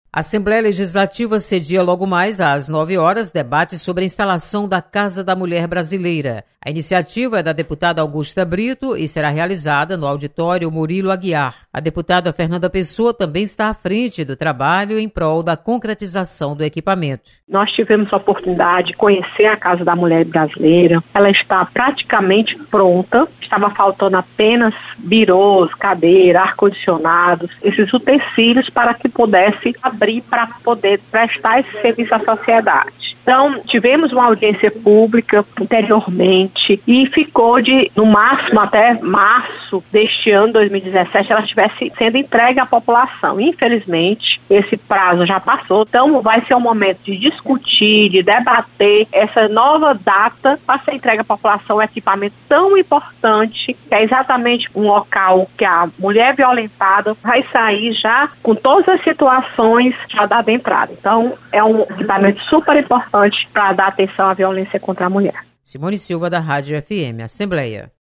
Instalação da Casa da Mulher Brasileira é tema de debate na Assembleia. Repórter